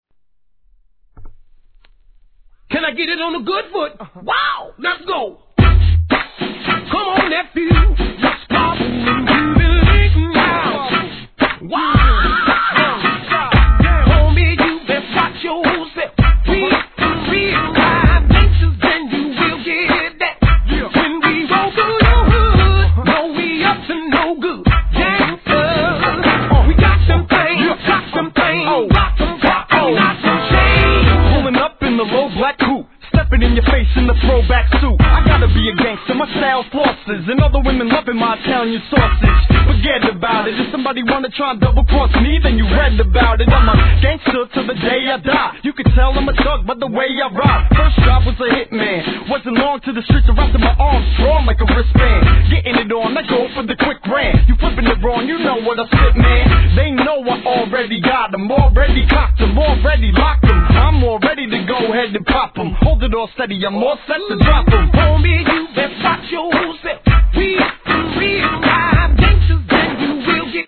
G-RAP/WEST COAST/SOUTH
A面はアップテンポのトラックに歌うようなフックがSOUL FULLな